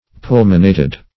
pulmonated - definition of pulmonated - synonyms, pronunciation, spelling from Free Dictionary
Pulmonated \Pul"mo*na`ted\